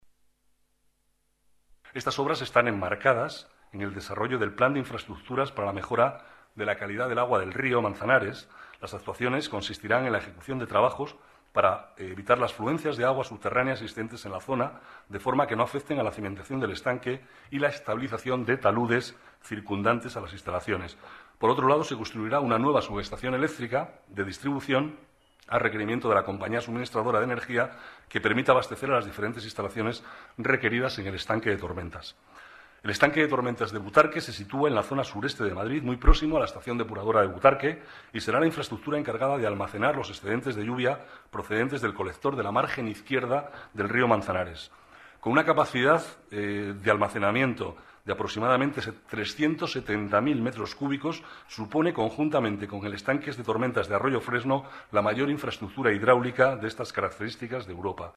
Nueva ventana:Declaraciones del vicealcalde, Manuel Cobo: Estanque de Tormentas de Butarque